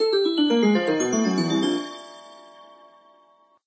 scale_descending.ogg